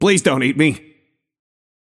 Shopkeeper voice line - Please don’t eat me.